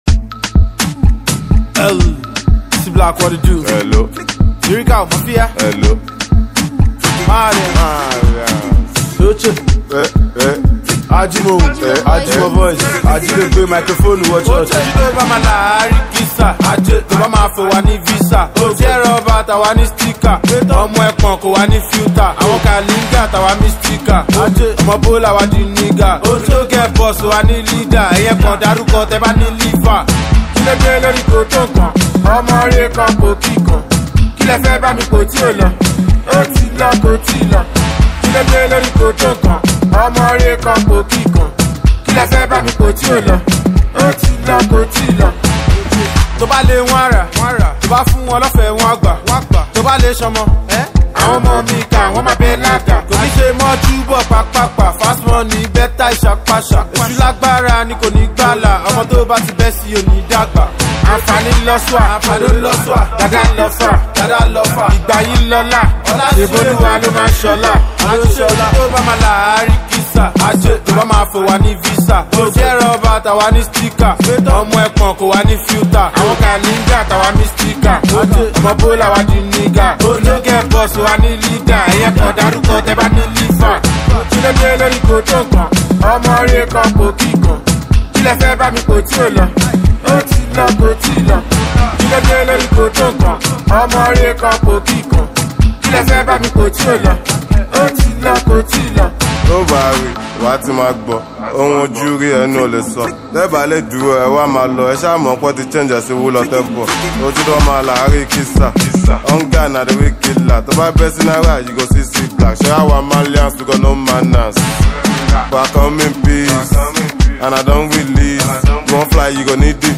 Marlian dance kind of song